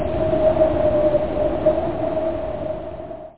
windfadeout.mp3